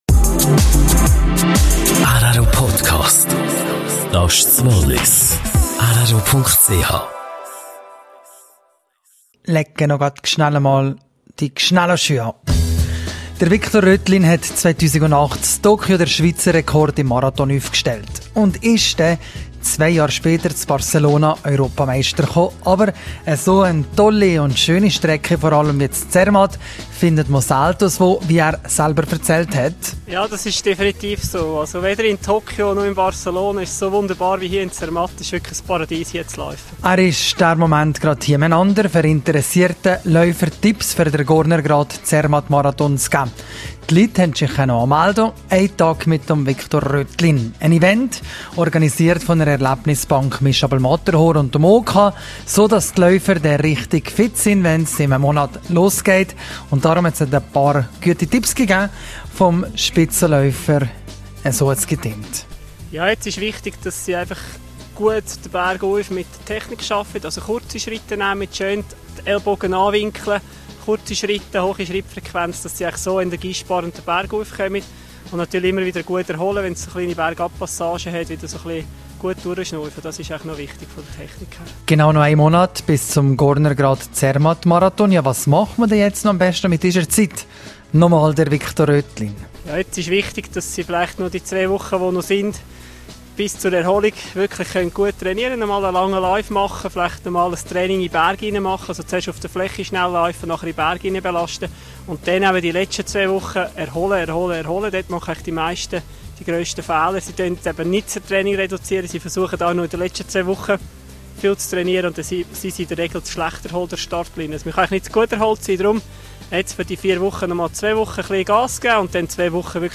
Mehr zu Viktor Röthlin, seine Marathon-Tipps, gebrochenen Rekorden, Rösti als Vorbereitung und eine geplante Matterhorn-Besteigung lesen Sie in der WB-Ausgabe vom Freitag./tad Gornergrat Zermatt Marathon: Interview mit Viktor Röthlin.